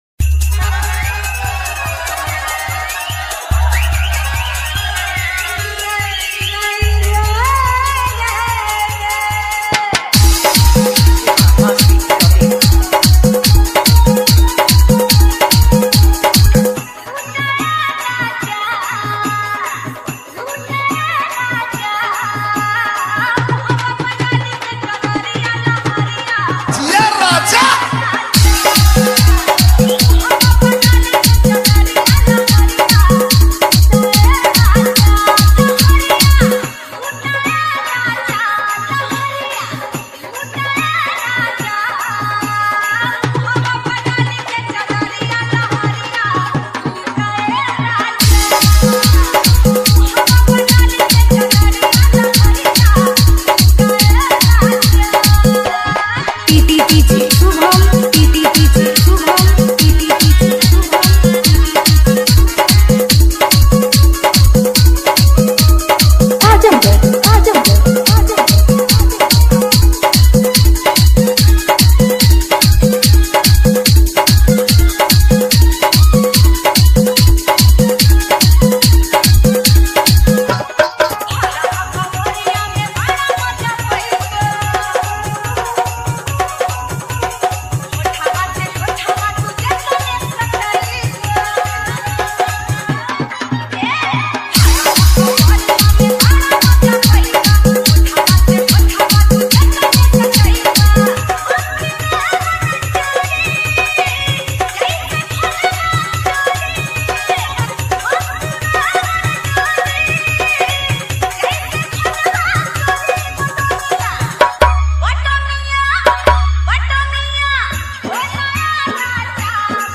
Bhojpuri Romantic DJ Remix